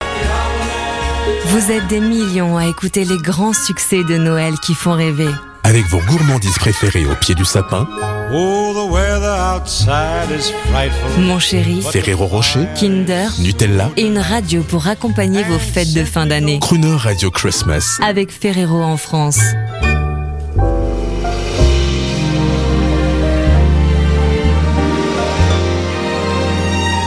4 exemples de messages Ferrero pour Crooner Christmas, en contexte :
Crooner_Ferrero_produits.mp3